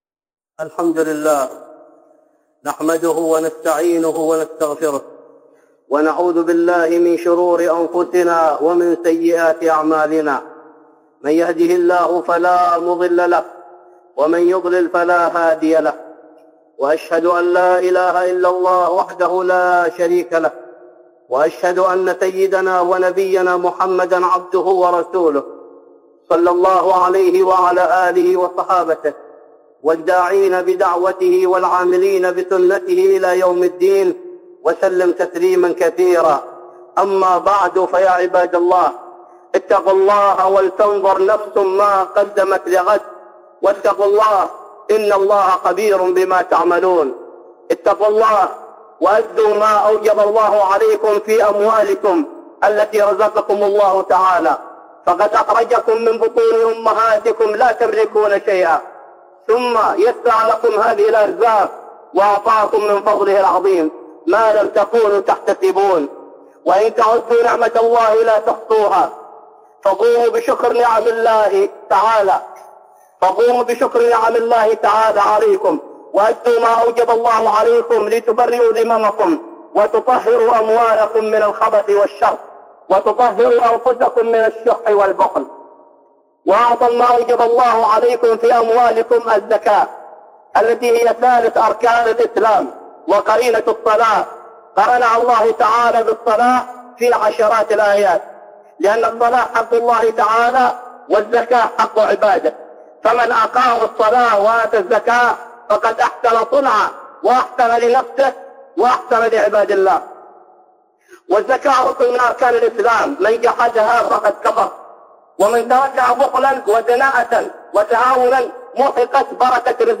خطبة جمعة بعنوان